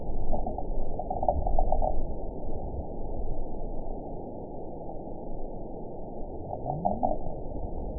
event 917954 date 04/23/23 time 10:43:49 GMT (2 years, 7 months ago) score 7.48 location TSS-AB03 detected by nrw target species NRW annotations +NRW Spectrogram: Frequency (kHz) vs. Time (s) audio not available .wav